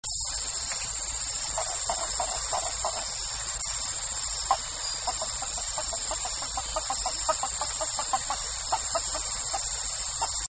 chant: chante sur la végétation à environ un mètre de hauteur du bord des cours d'eau lents, audible à quelques mètres, sorte de gloussement de poule:
chant geographica.mp3